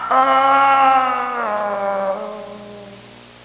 Groan Sound Effect
Download a high-quality groan sound effect.
groan-1.mp3